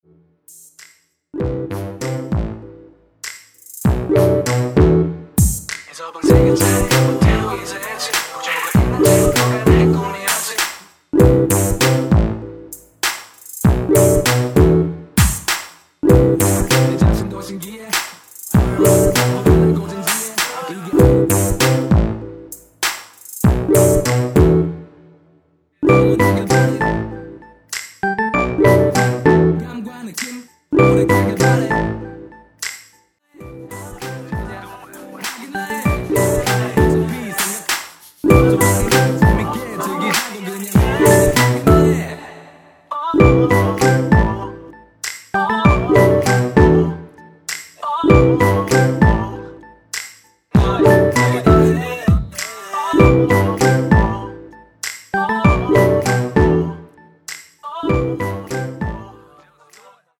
코러스 포함된 MR 입니다(미리듣기 참조)
Em
앞부분30초, 뒷부분30초씩 편집해서 올려 드리고 있습니다.
중간에 음이 끈어지고 다시 나오는 이유는